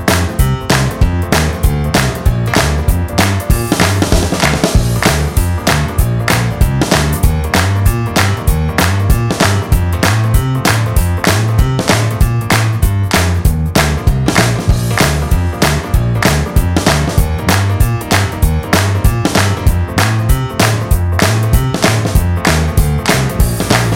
No Guitars Pop (1980s) 3:02 Buy £1.50